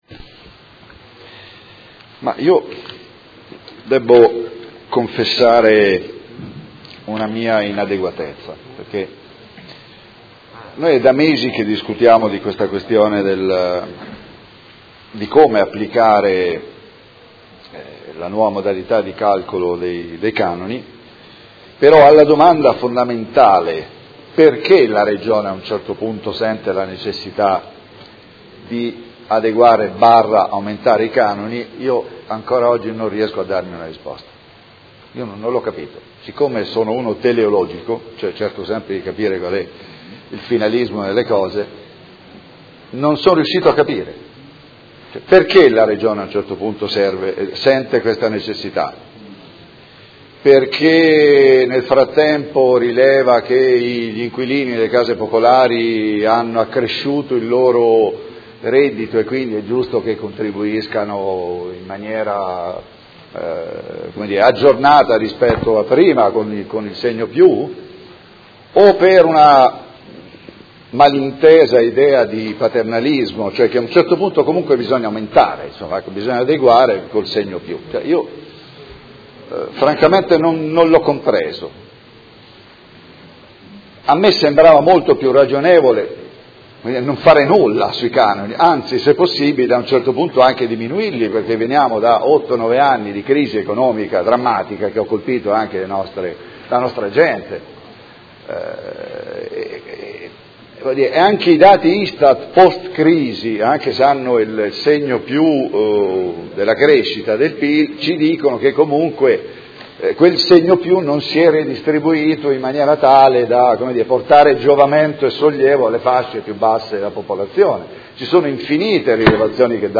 Seduta del 5/04/2018. Dibattito su proposta di deliberazione: Approvazione Regolamento per la definizione delle modalità di calcolo e di applicazione dei canoni di locazione degli alloggi di edilizia residenziale pubblica con decorrenza 1 ottobre 2017, Ordini del Giorno ed emendamento